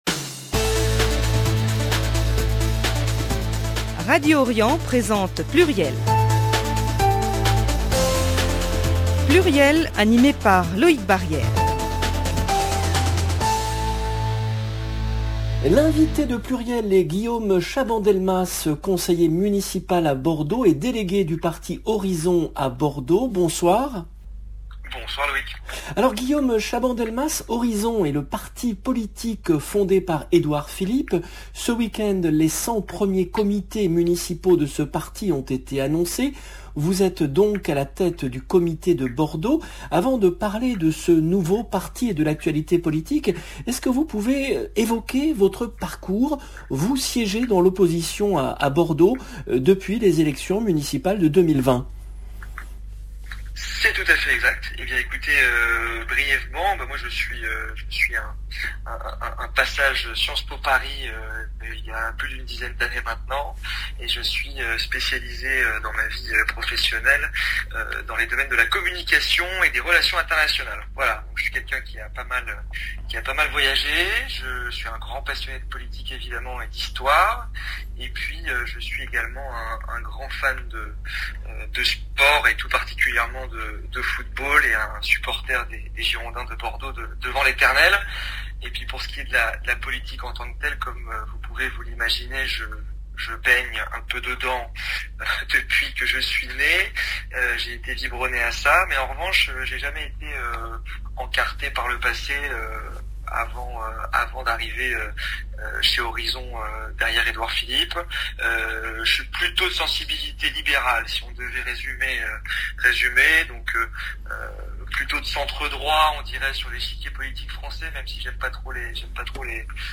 Guillaume Chaban-Delmas, conseiller municipal, délégué du parti Horizons à Bordeaux